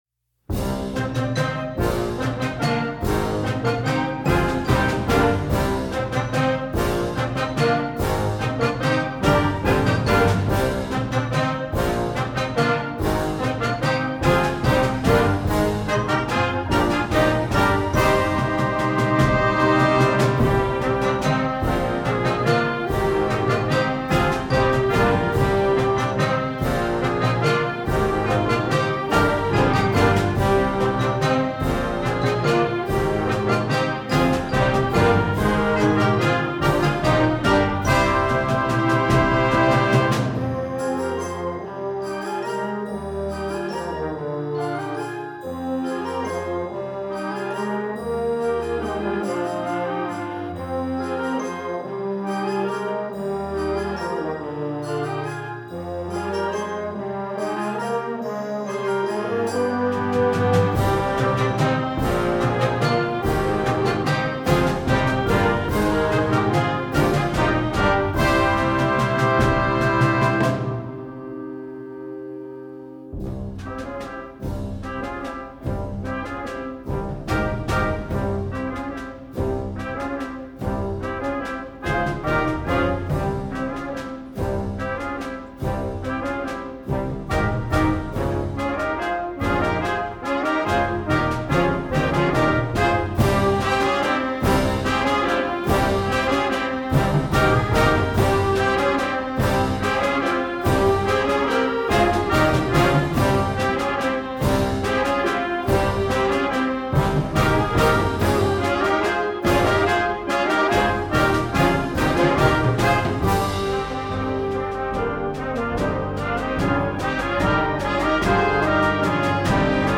an epic minor piece